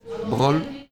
Brol play all stop prononciation Brol ↘ exemple Et le 'brol’ c’est du brol.
brol-pron.mp3